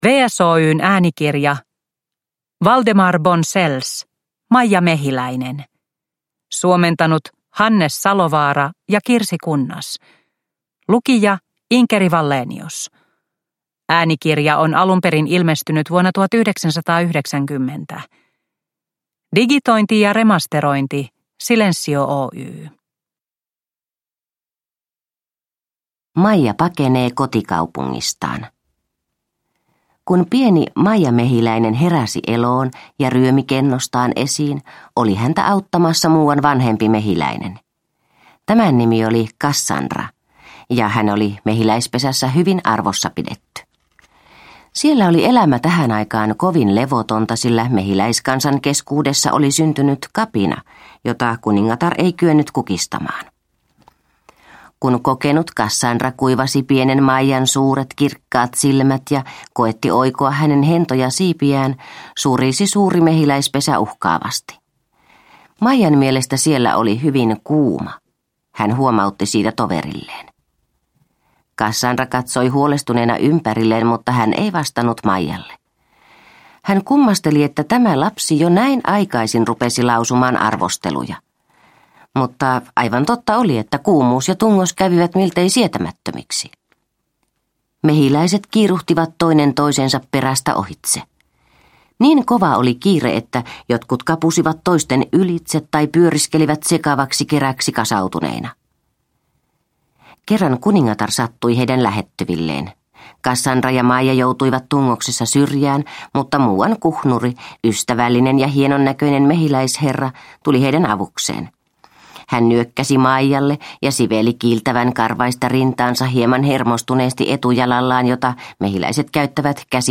Maija Mehiläinen – Ljudbok – Laddas ner
Sisukkaan Maija Mehiläisen seikkailut äänikirjana!